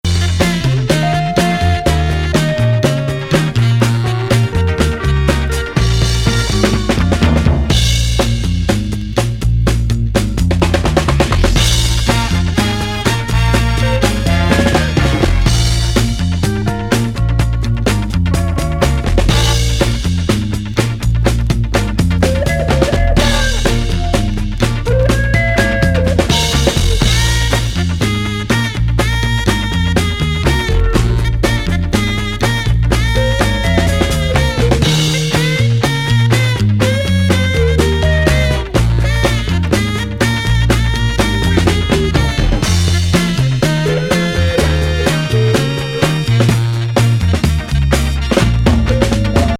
豪華メンツ参加FUSION ALBUM!!